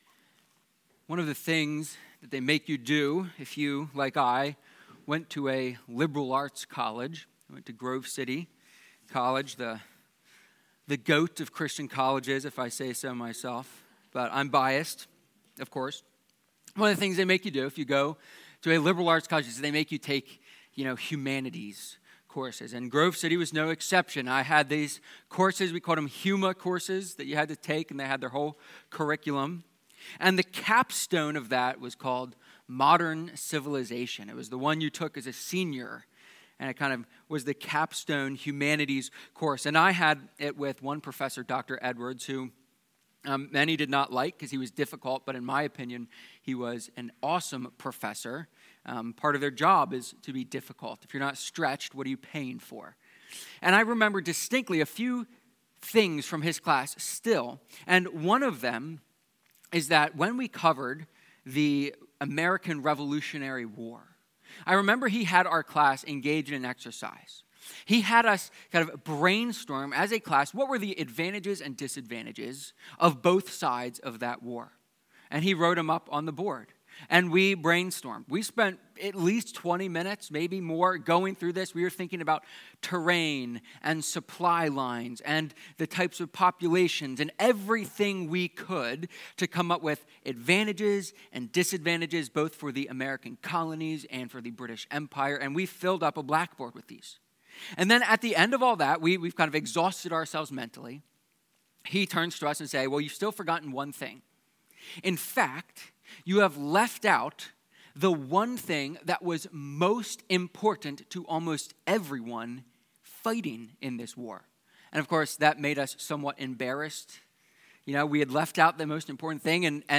Psalm-127-sermon.mp3